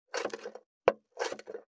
475,切る,包丁,厨房,台所,野菜切る,
効果音